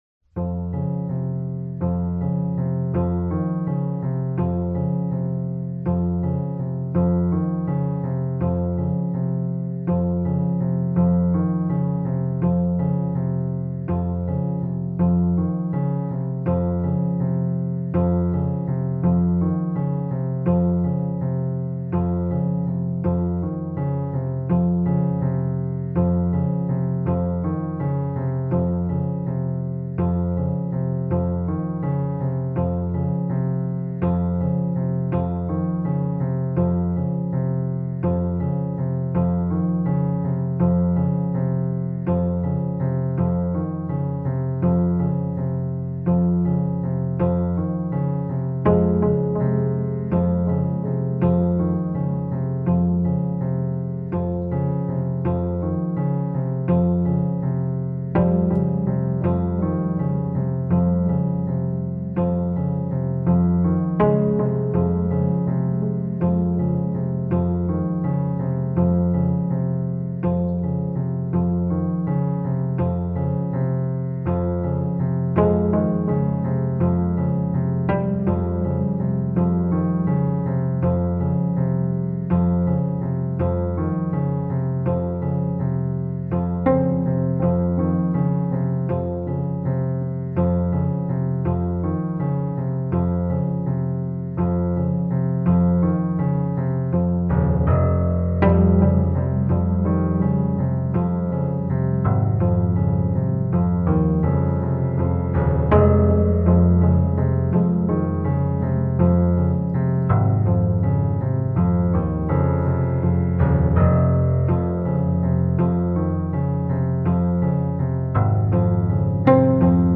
pianistisch sehr beeindruckend
feine Balance von Strenge und Freiheit
Dieses Pattern erklingt 8 mal.
Das Cymbal habe ich danach aufgenommen.